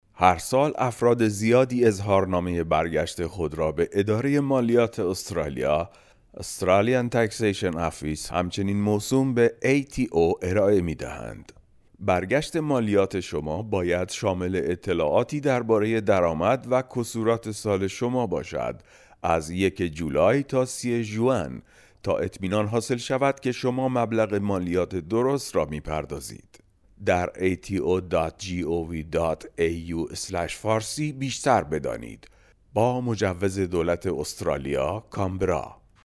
Male
Adult
Demo